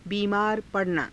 ふつう